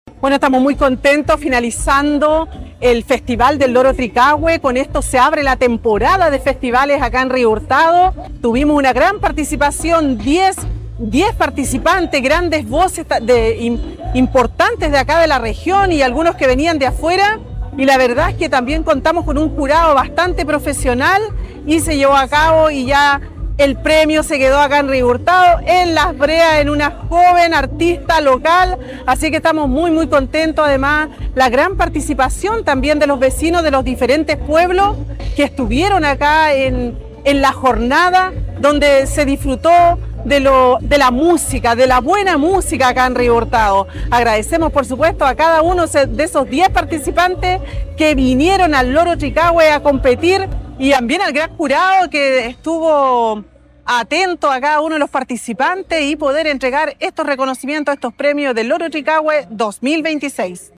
Cuna-alcaldesa.mp3